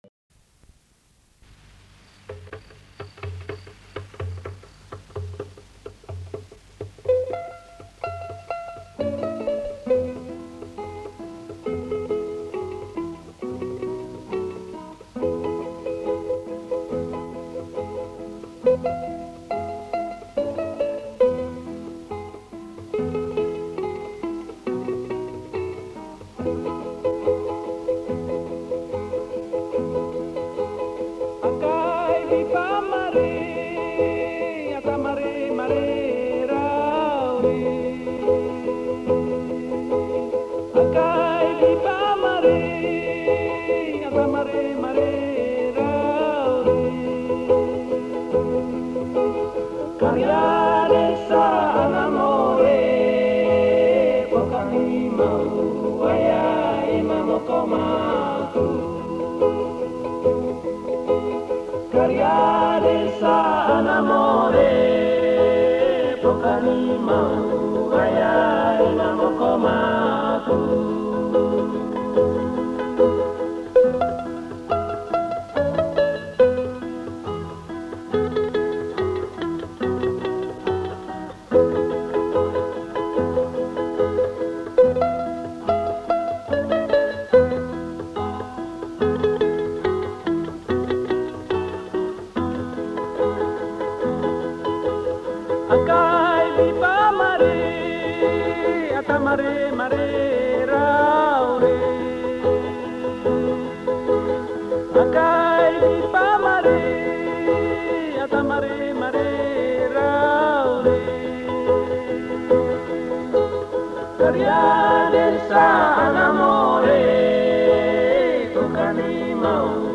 digitised from an old analogue tape